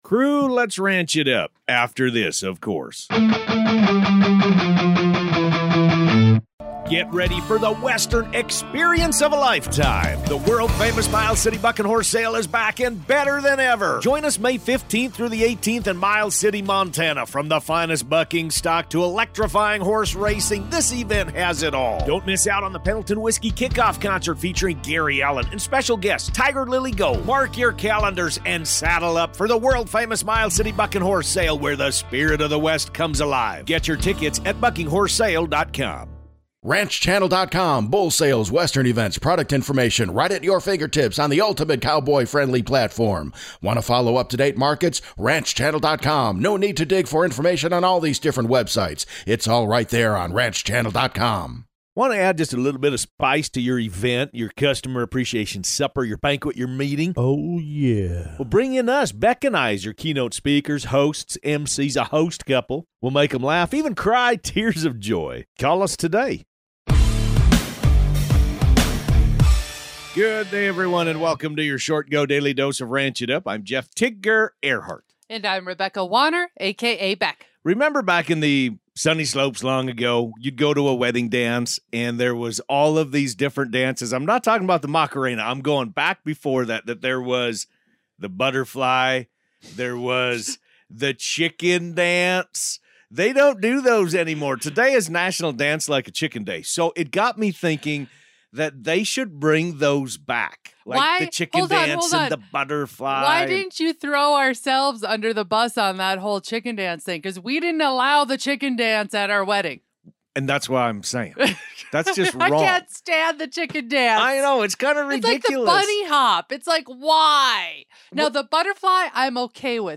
Expect insightful (and hilarious) commentary, listener shout-outs, and everything you need to stay in the loop on all things ranch. It's your daily squeeze of ranchy goodness!